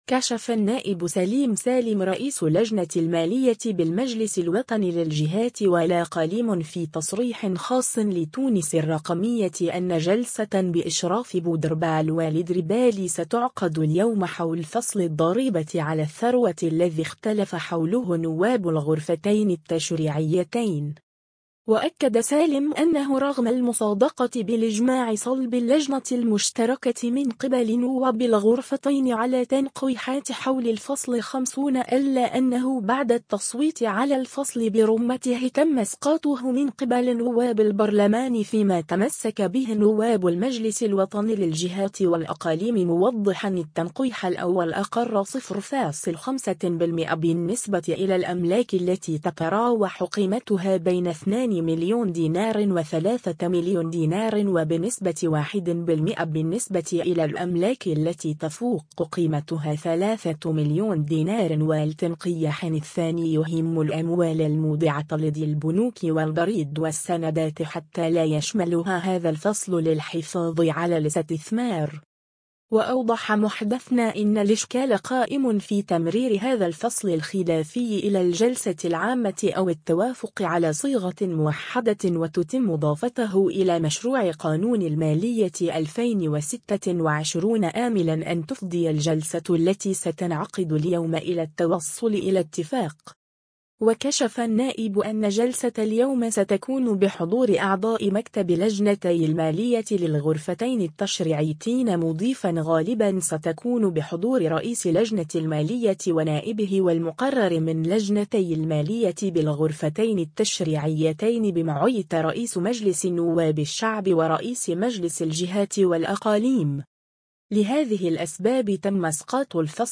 كشف النائب سليم سالم رئيس لجنة المالية بالمجلس الوطني للجهات والاقاليم في تصريح خاص لـ”تونس الرقمية” أن جلسة بإشراف بودربالة والدربالي ستعقد اليوم حول فصل الضريبة على الثروة الذي اختلف حوله نواب الغرفتين التشريعيتين.